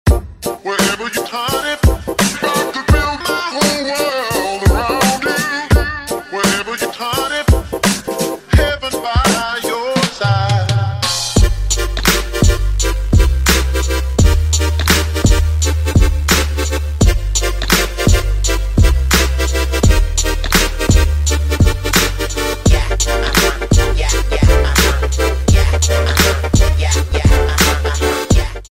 • Качество: 256, Stereo
Хип-хоп
Стиль: Future Funk, Hip-Hop